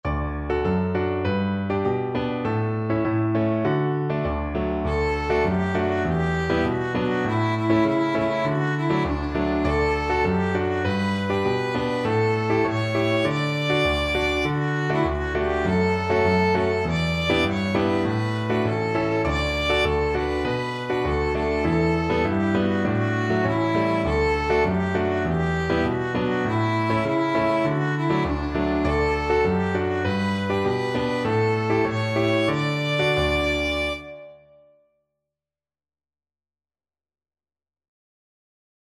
Violin
Traditional Music of unknown author.
D major (Sounding Pitch) (View more D major Music for Violin )
Joyfully
2/4 (View more 2/4 Music)
D5-D6